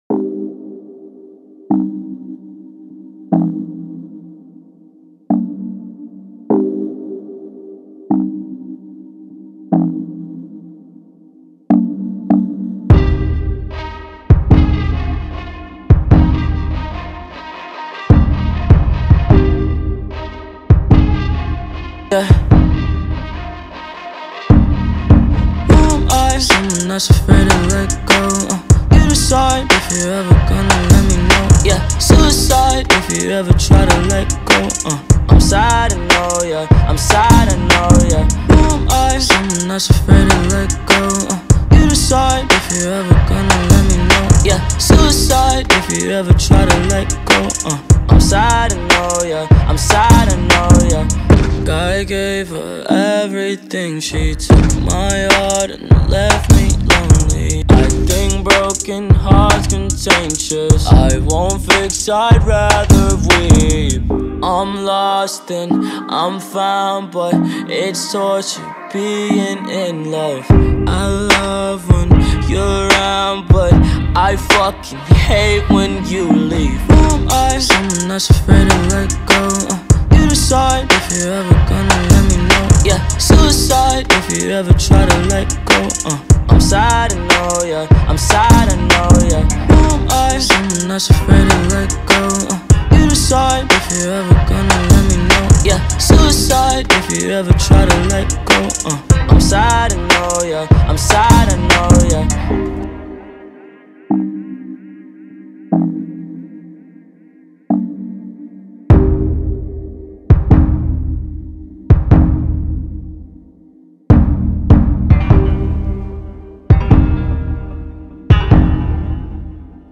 غمگین
غمگین خارجی